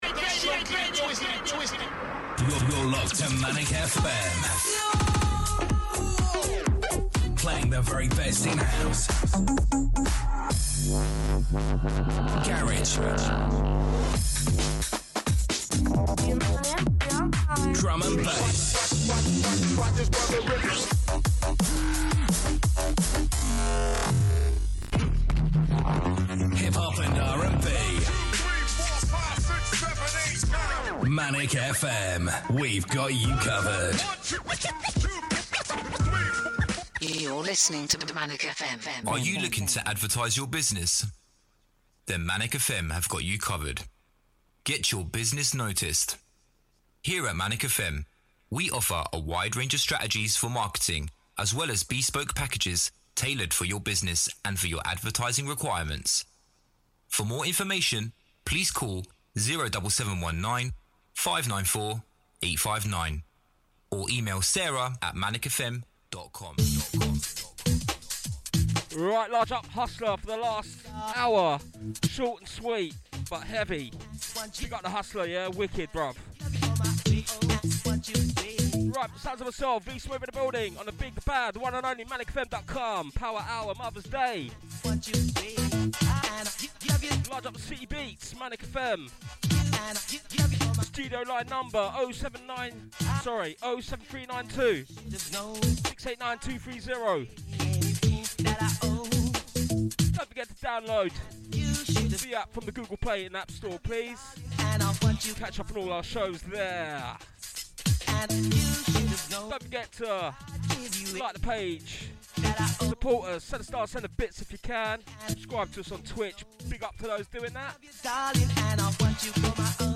fast & furious mixing action